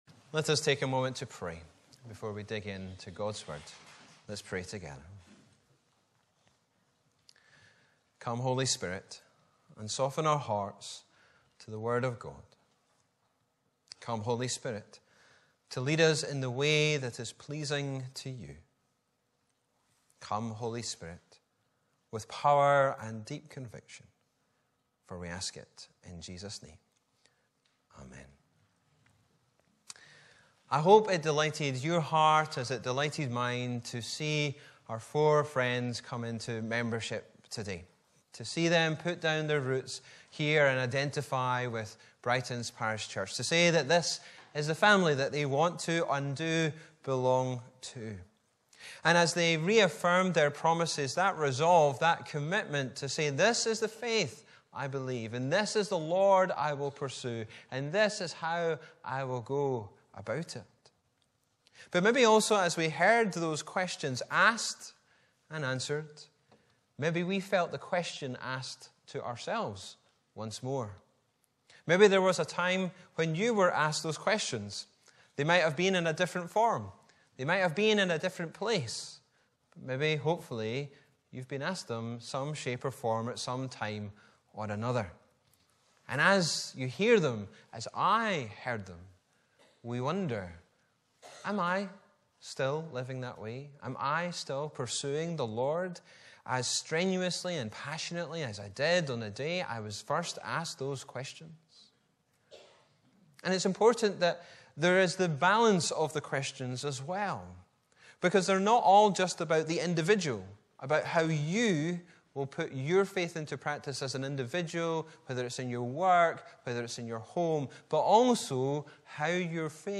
Mar 17, 2024 Called to: MP3 Subscribe to podcast Notes Sermons in this Series Preached on: Sunday 17th March 2024 The sermon text is available as subtitles in the Youtube video (the accuracy of which is not guaranteed).
Location: Brightons Parish Church